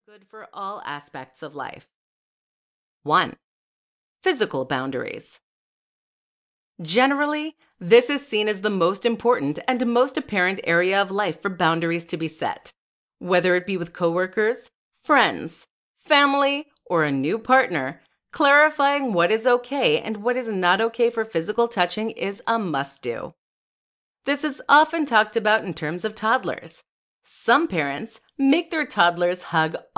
HealthyBoundariesAudioBookFull.mp3